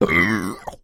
Звуки отрыжки
Отрыжка человека - альтернативный вариант